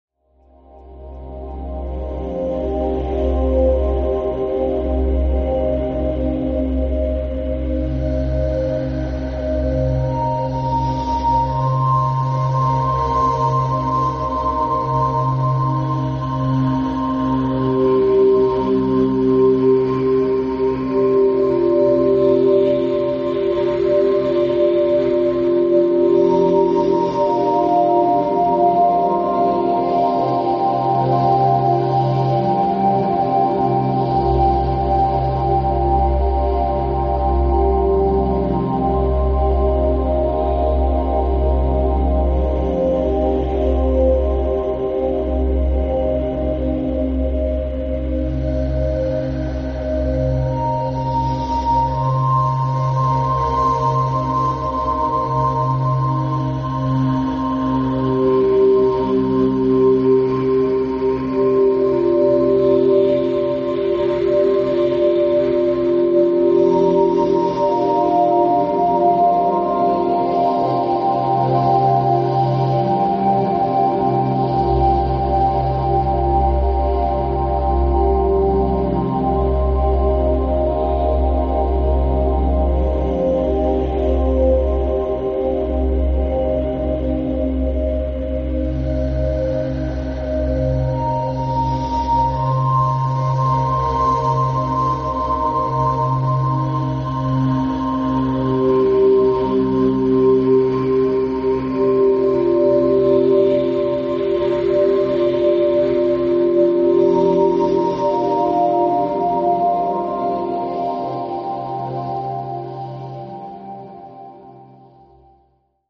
arcade-ambience.mp3